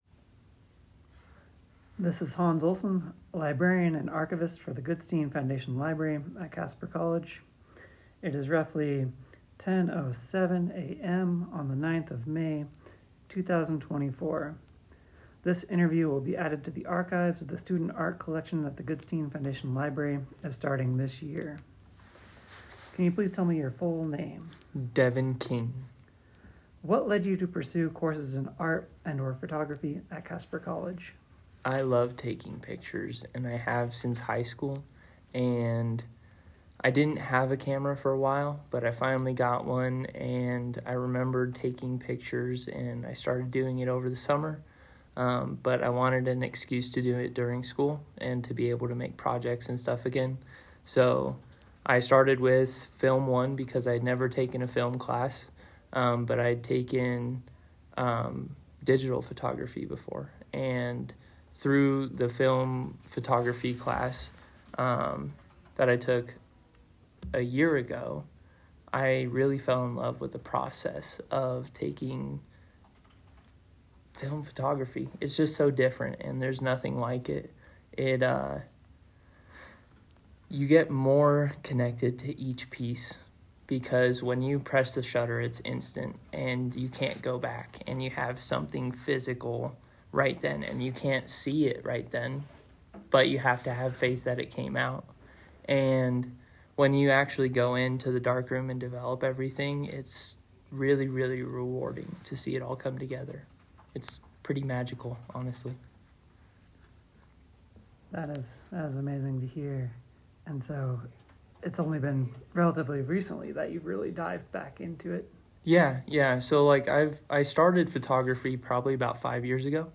Item sets Goodstein Foundation Library Student Art Collection Digital Archives Site pages Goodstein Foundation Library Student Art Collection Media Oral History on Ethereal Ephemerality